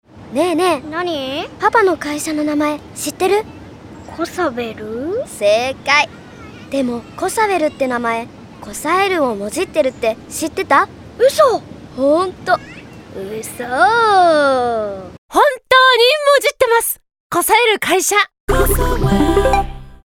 ラジオCM『噓のような本当の話』を認知のきっかけに！
『嘘のような本当の話』シリーズとして様々なシチュエーションの会話にすることで、「本当にある会社？」「なんの会社だろう？」という疑問から興味を持ってもらい、ネットなどで会社を検索してもらうきっかけにするのが、この作品のコンセプトとなっています。